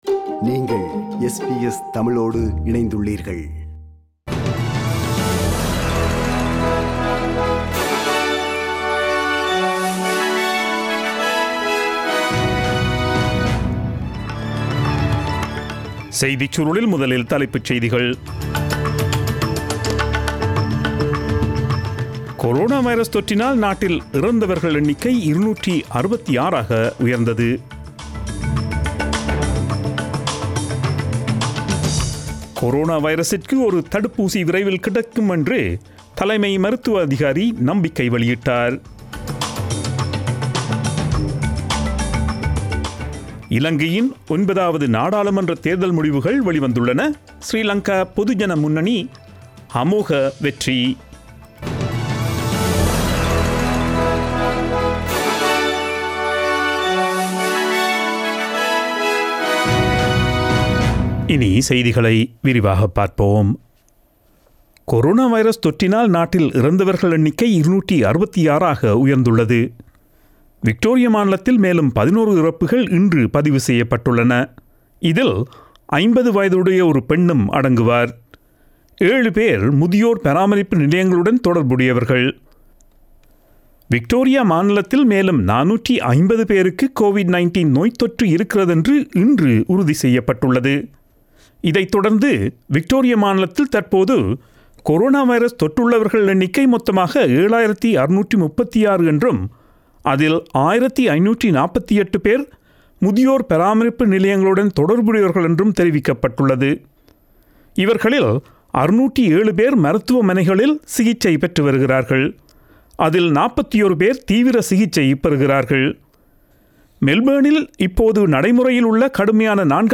Australian news bulletin aired on Friday 07 August 2020 at 8pm.